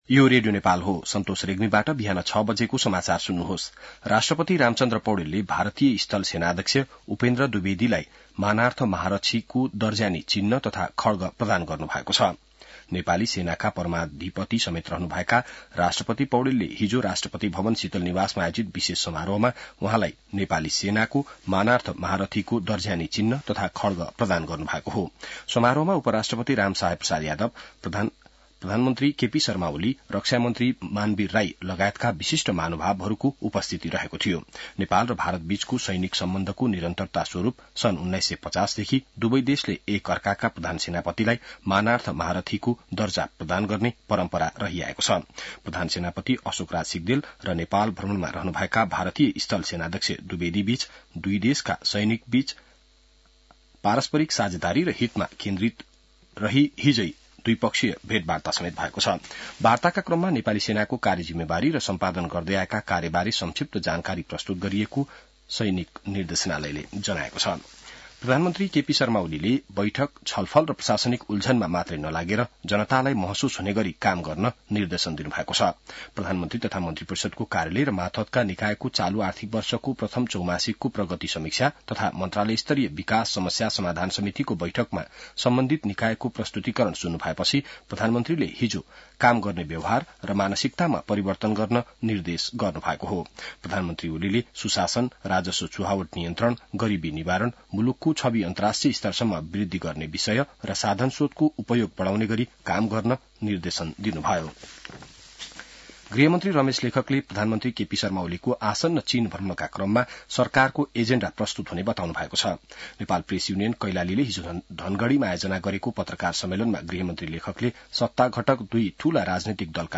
बिहान ६ बजेको नेपाली समाचार : ८ मंसिर , २०८१